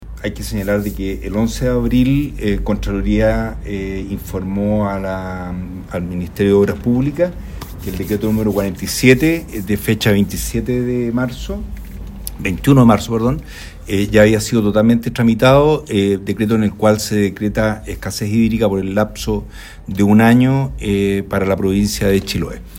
Al respecto entregó declaraciones el delegado provincial, Marcelo Malagueño.